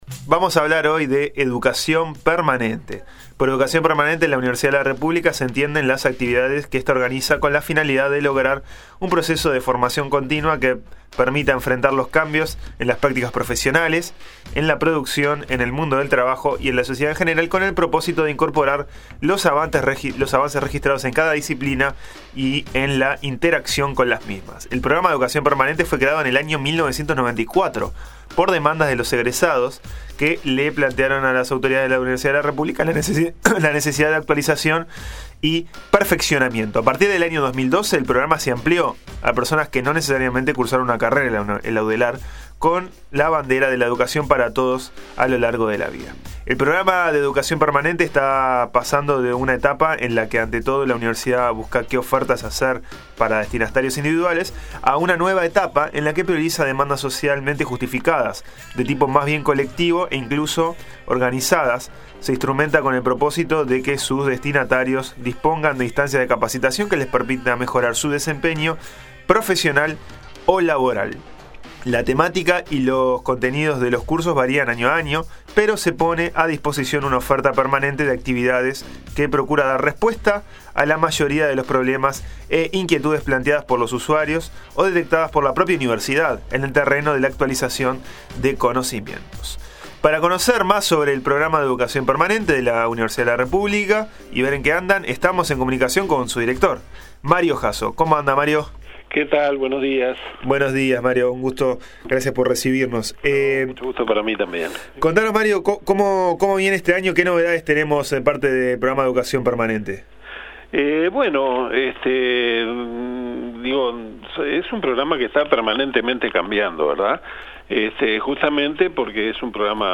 Audio: Educacion permanente a lo largo de toda la vida, entrevista